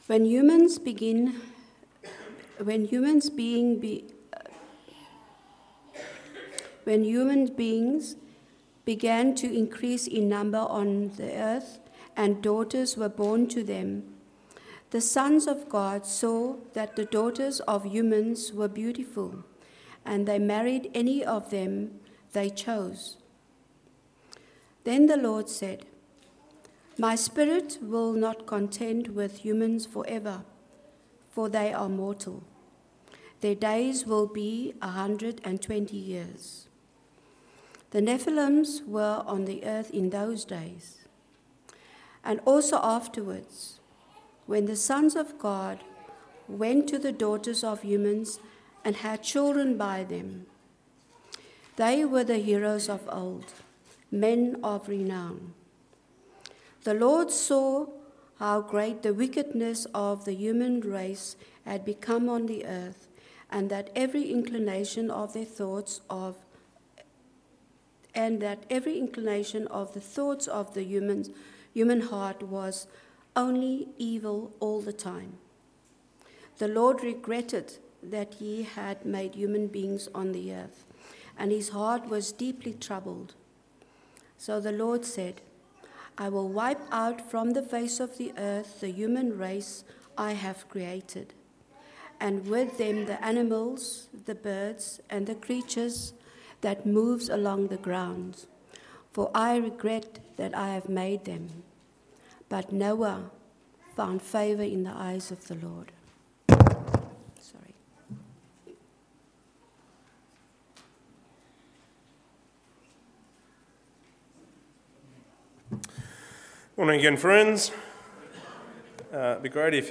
Service Type: Rosemeadow AM